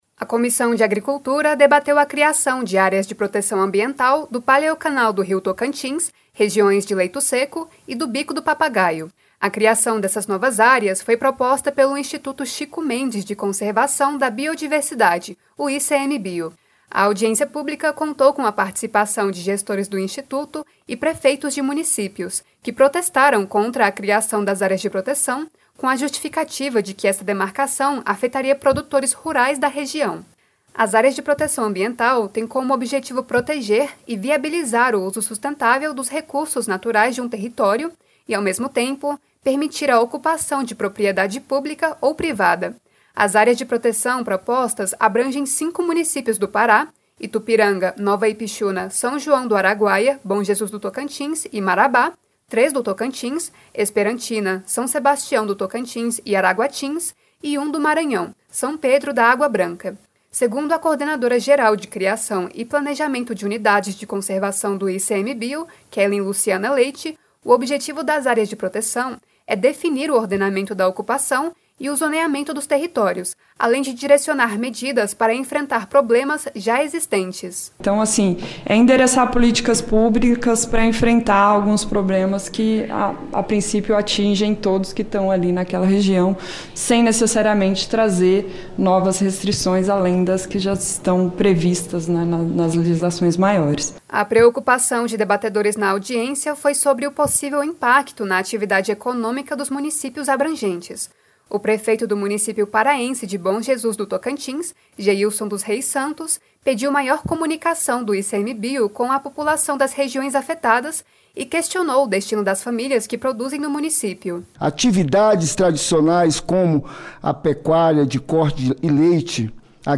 Audiência Pública